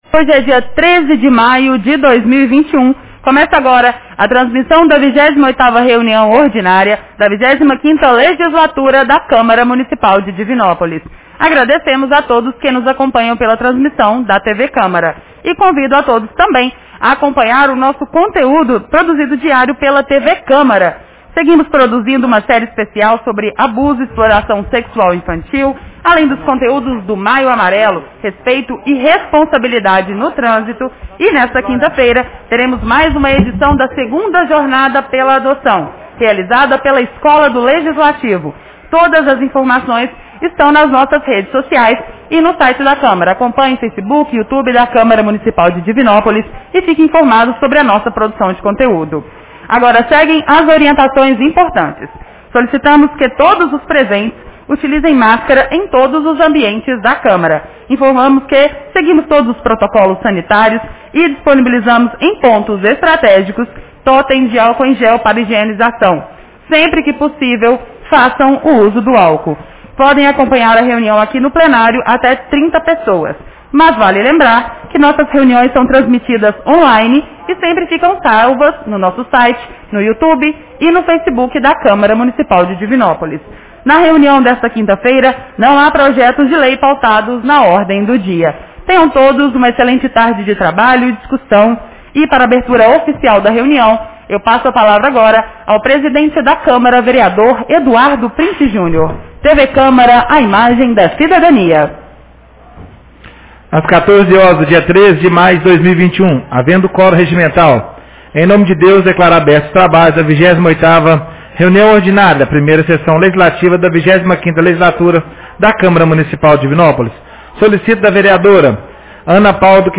Reunião Ordinária 28 de 13 de maio 2021